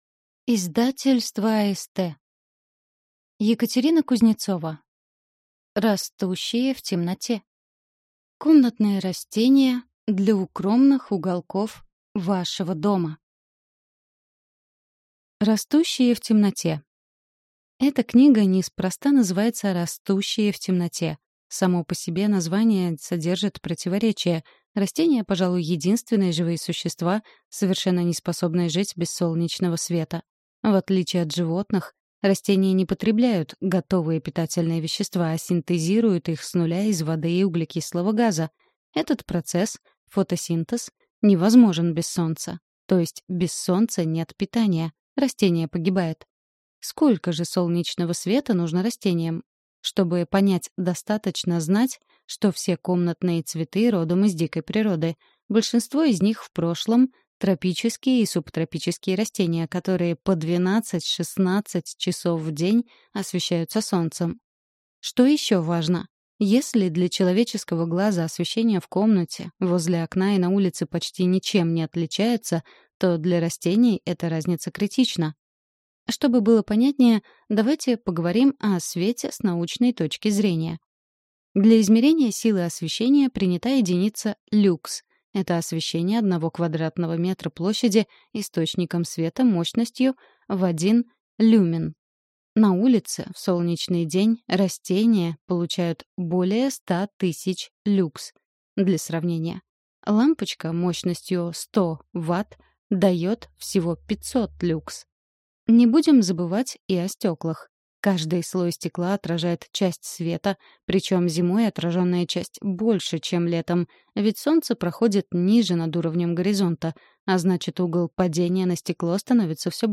Аудиокнига Растущие в темноте. Комнатные растения для укромных уголков вашего дома | Библиотека аудиокниг